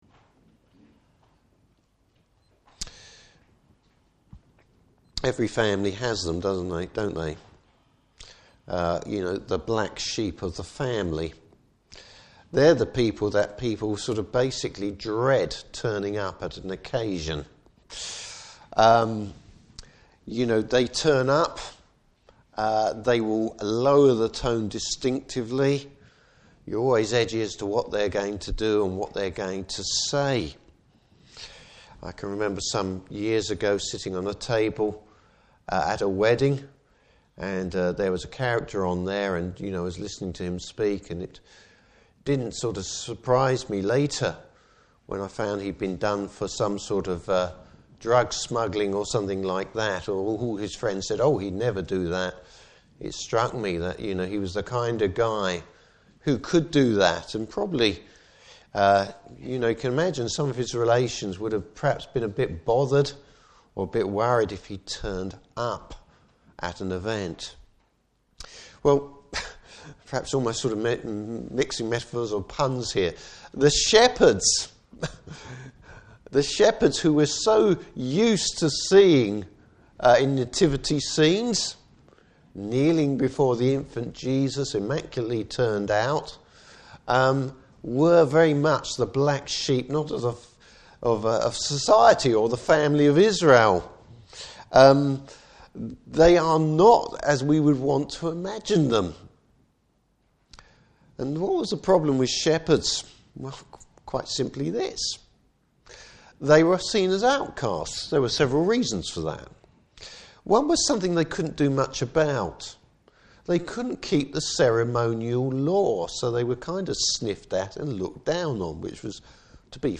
Service Type: Morning Service Bible Text: Luke 2:8-20.